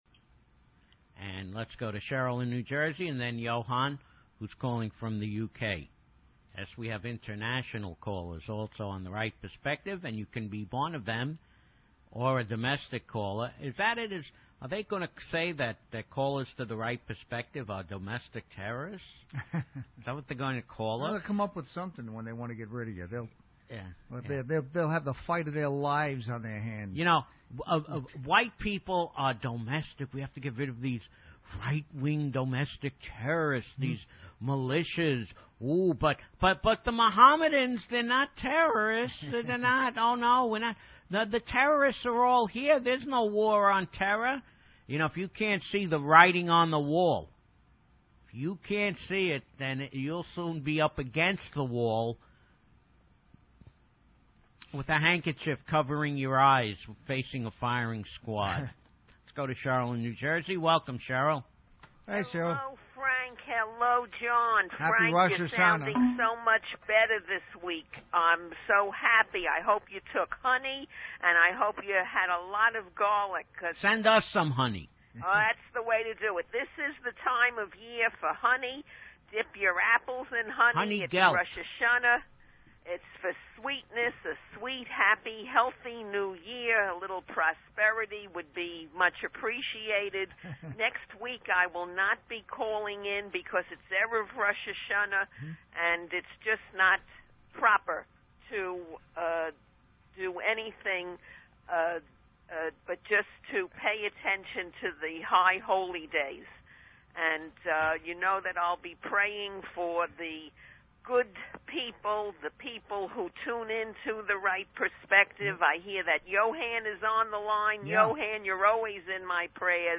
Tags: South Africa Propaganda Apartheid The Right Perspective Talk Radio